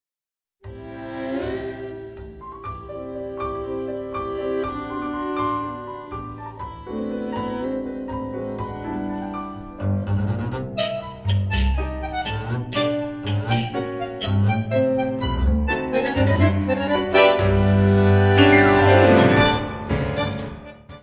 piano, congas
violino
contrabaixo
Bandoneón
: stereo; 12 cm
Music Category/Genre:  World and Traditional Music